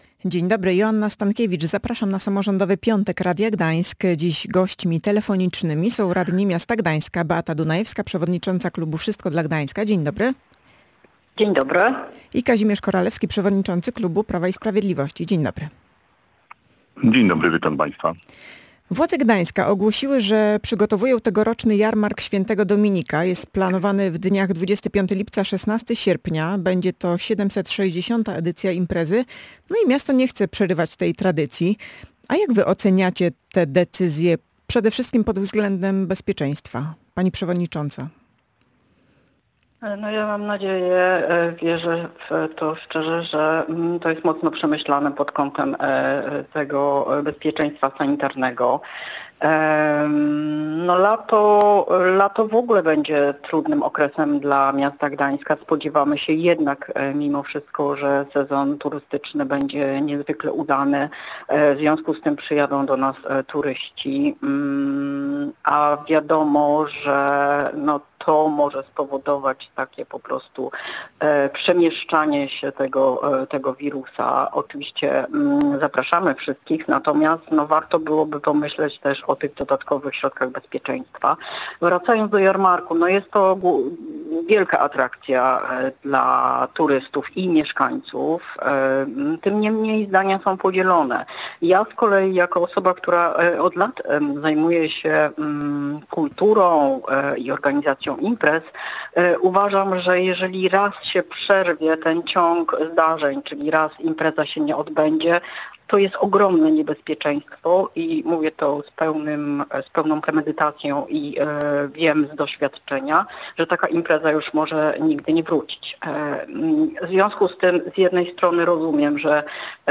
O tym właśnie dyskutowali radni – Beata Dunajewska, przewodnicząca klubu Wszystko dla Gdańska oraz Kazimierz Koralewski, przewodniczący klubu PiS.